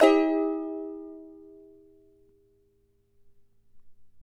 CHAR D MN  U.wav